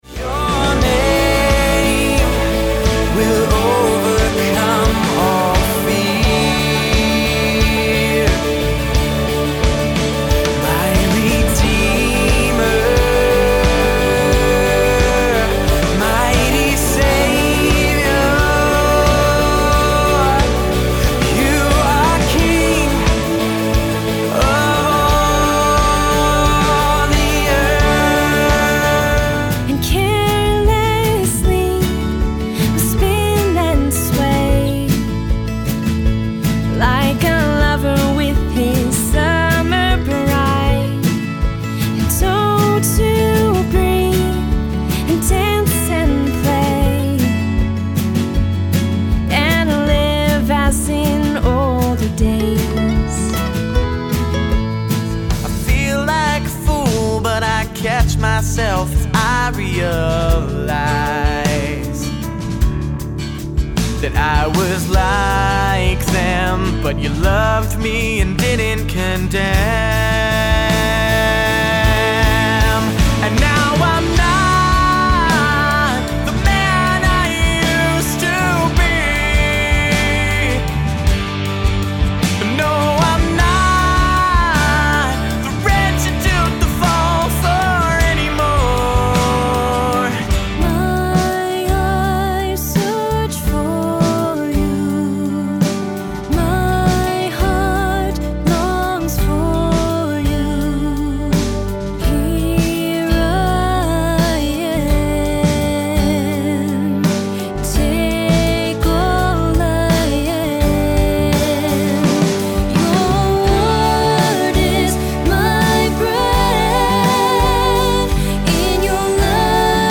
Bass player